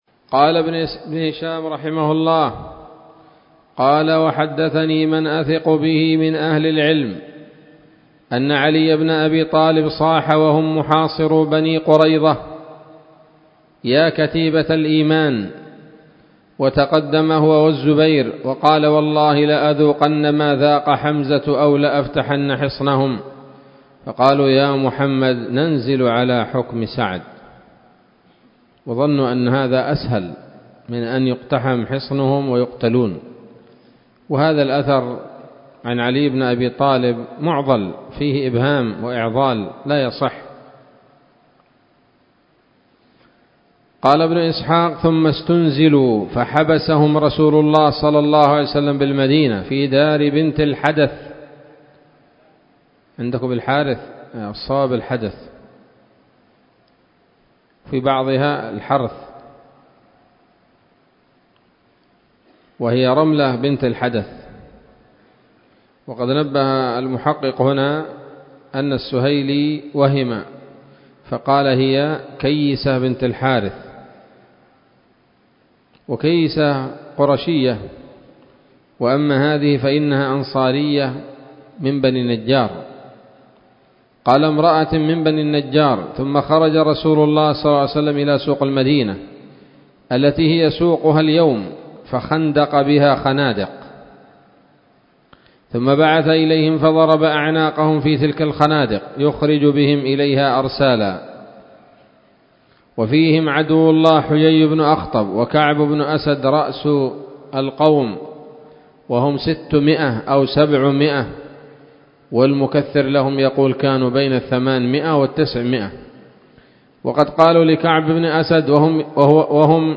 الدرس الثامن بعد المائتين من التعليق على كتاب السيرة النبوية لابن هشام